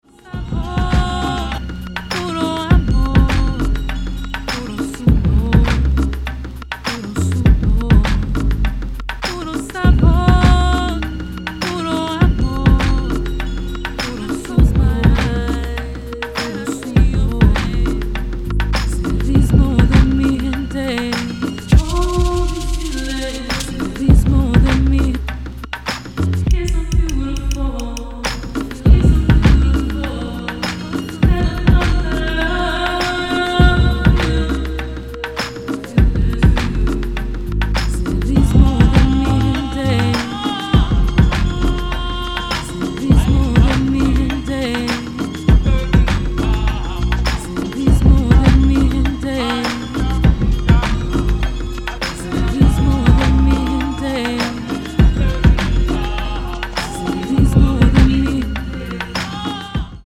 House Soul Detroit Vintage